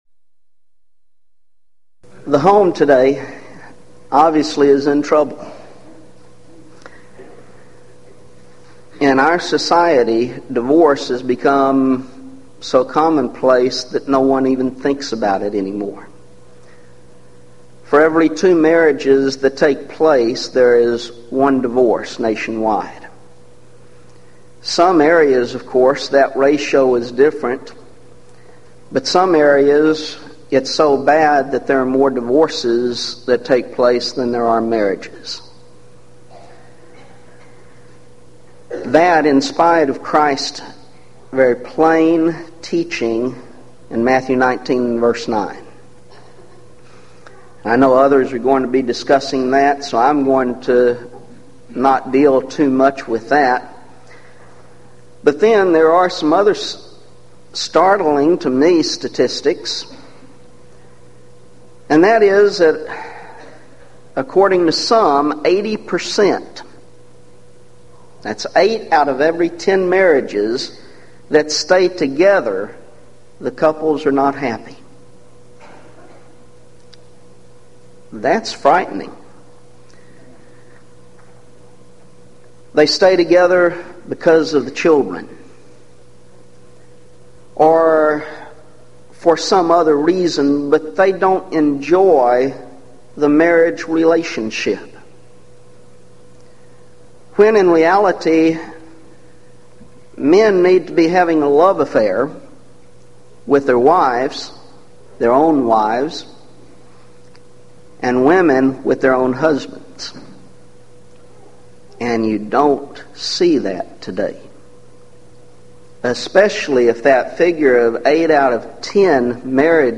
Event: 1997 Gulf Coast Lectures Theme/Title: The Wise Home And The Cautious Church